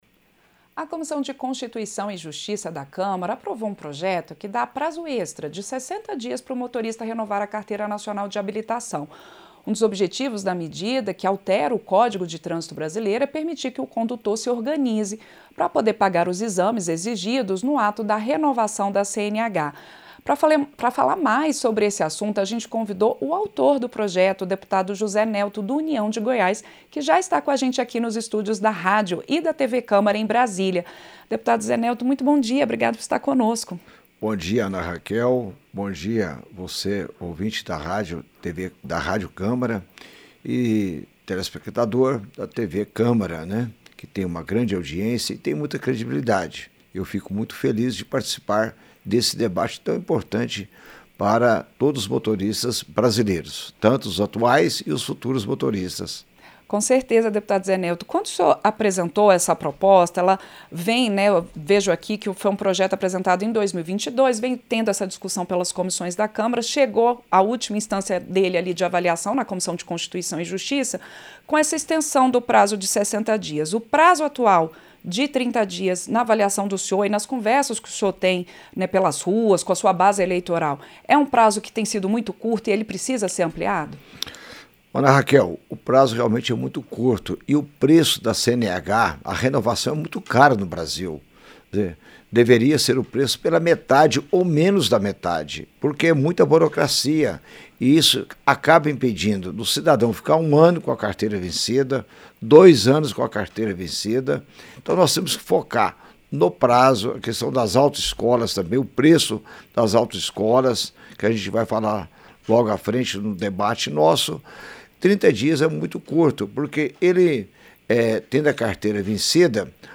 Entrevista - Dep. José Nelto (UNIÃO-GO)